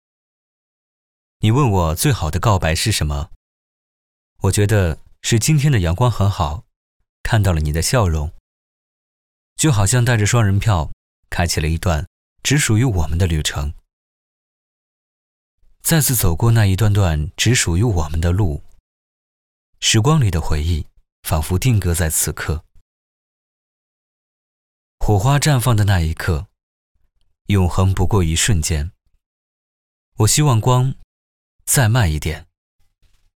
男10号
情人节(旁白)